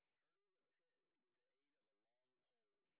sp09_train_snr30.wav